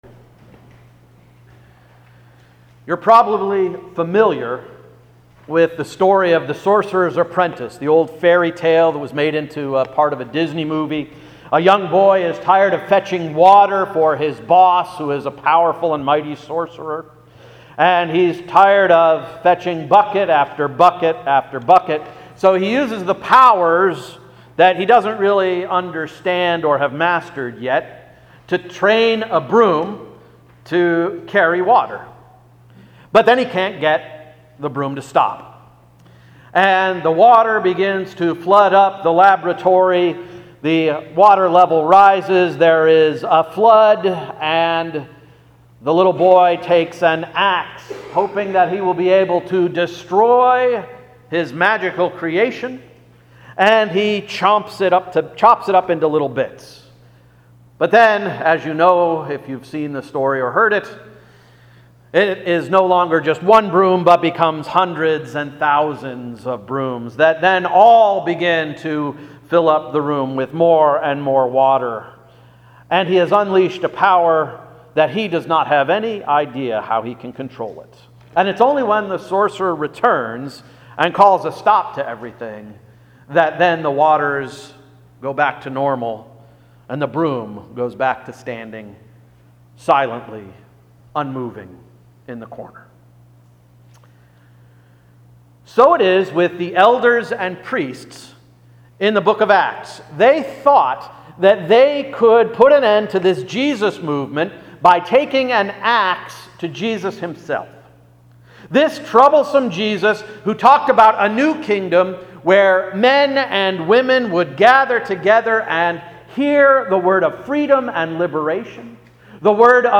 Sermon of June 11, 2017 — “Keep On Keepin On”